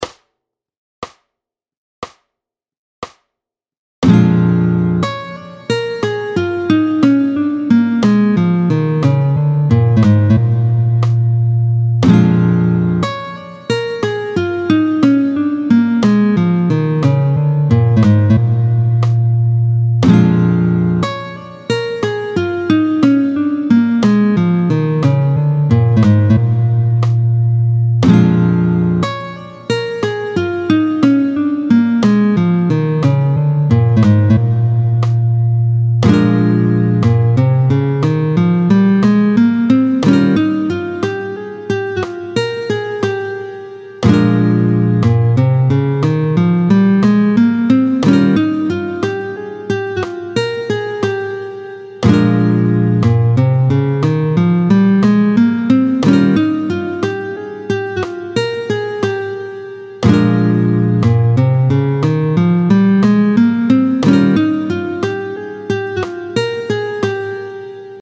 31-07 Ajout de tierce majeure ; gamme de Lab, tempo 60
31-08-Tierce-majeure-Gamme-Lab.mp3